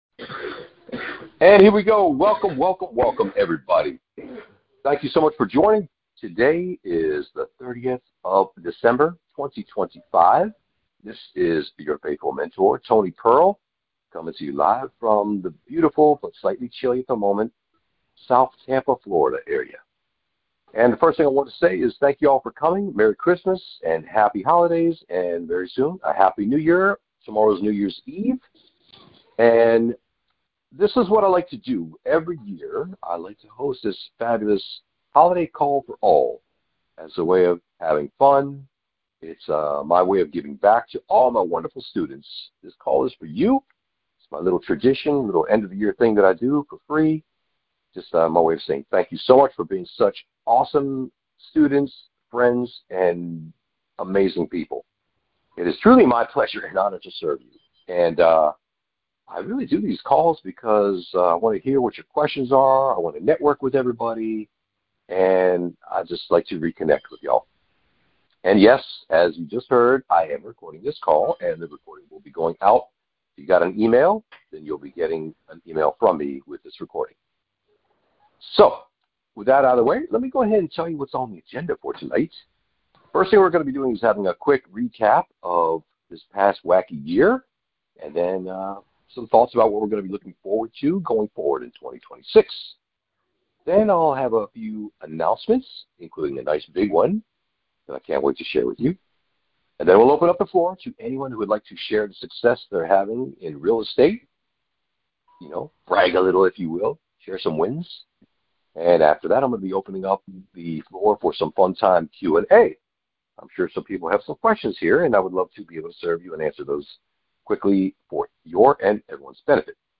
Holiday Group Coaching Call Replay from Monday 30 Dec 2025